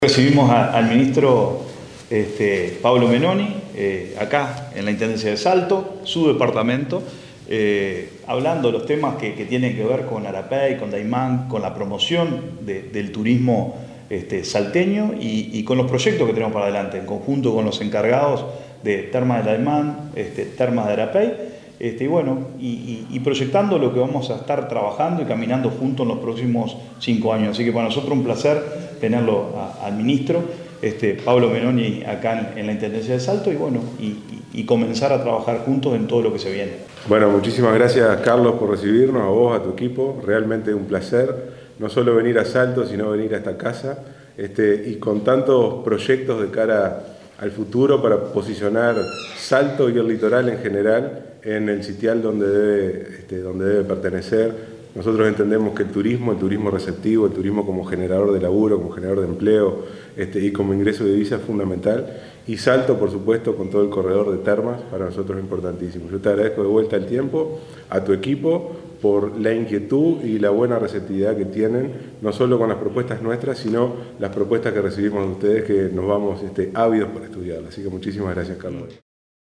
Hablan Ministro de Turismo Pablo menoni, intendente de Salto, Carlos Albisu.